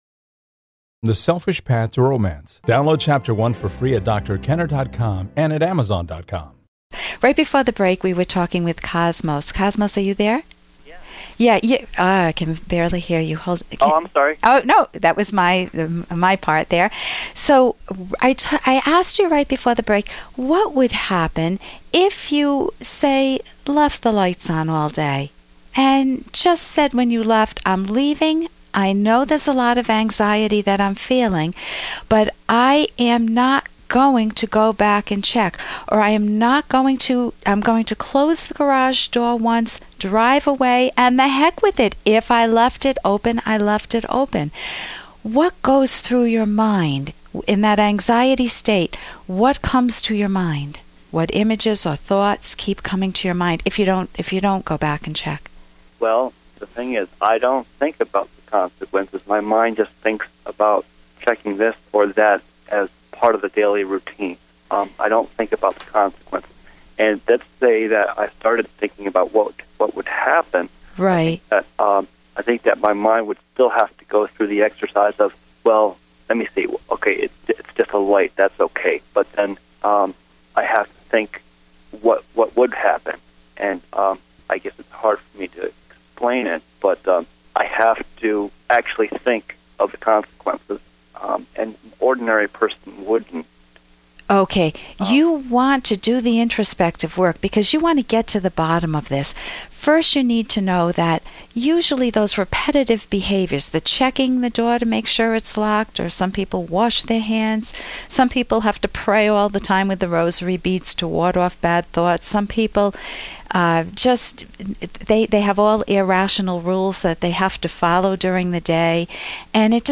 Episode from The Rational Basis of Happiness® radio show